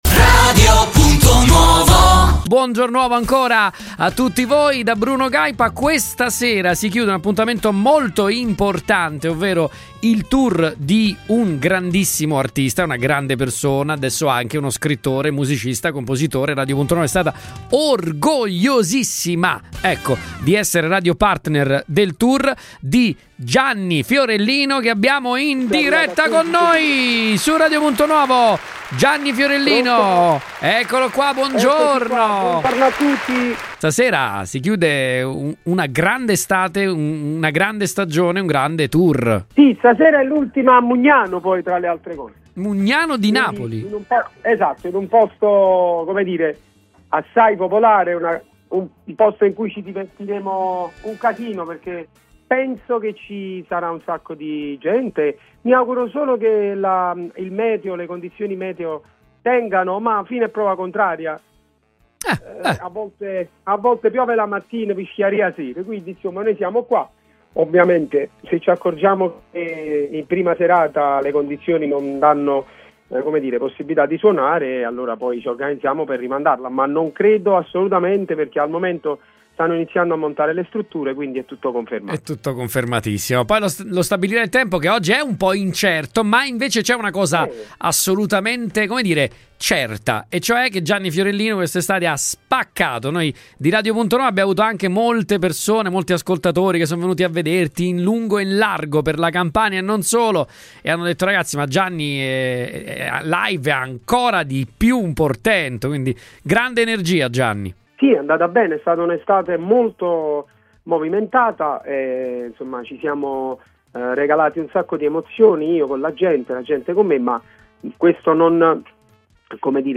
La grande stagione, iniziata lo scorso 1° maggio a Casalnuovo, trova il suo gran finale in un contesto “assai popolare,” come anticipato dall’artista stesso durante l’intervista rilasciata a Radio Punto Nuovo, partner radiofonico ufficiale del tour.